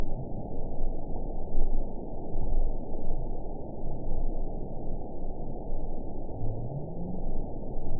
event 912649 date 03/30/22 time 23:53:30 GMT (3 years, 1 month ago) score 9.31 location TSS-AB01 detected by nrw target species NRW annotations +NRW Spectrogram: Frequency (kHz) vs. Time (s) audio not available .wav